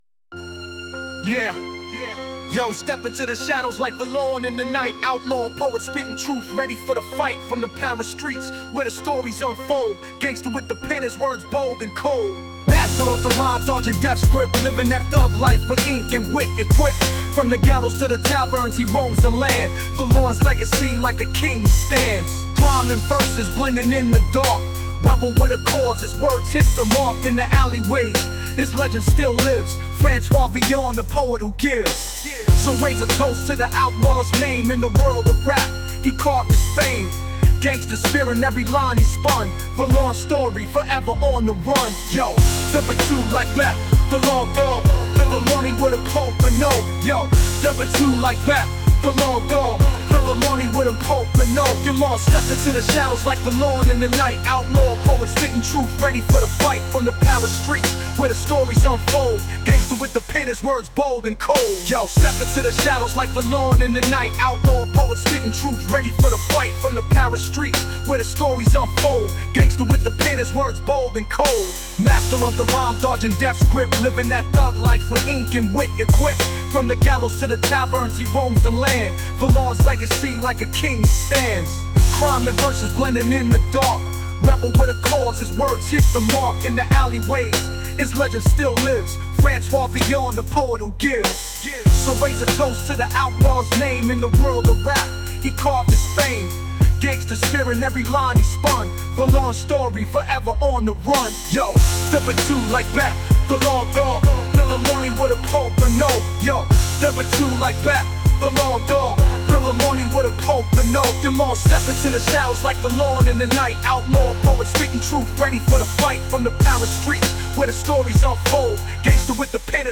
Rebel Francois Villon – Gangsta rap